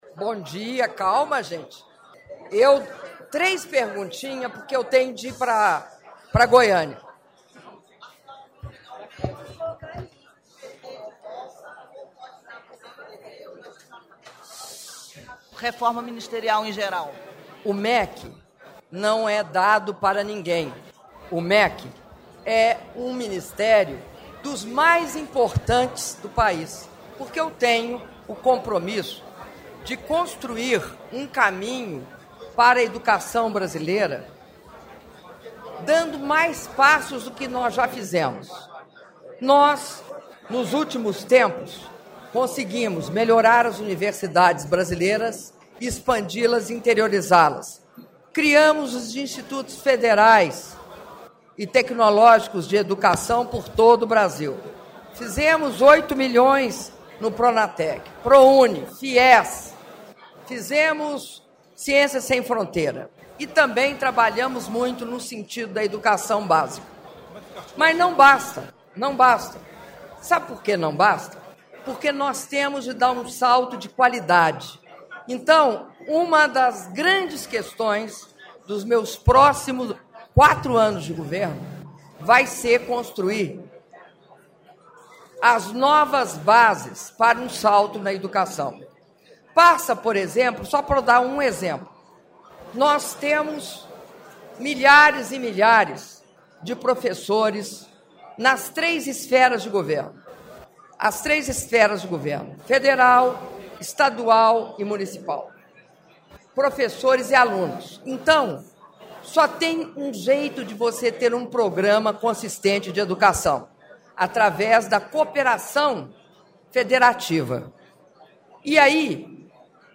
Áudio da entrevista coletiva concedida pela presidenta Dilma Rousseff após cerimônia de anúncio de Medidas de Modernização do Futebol - Brasília (06mim42s)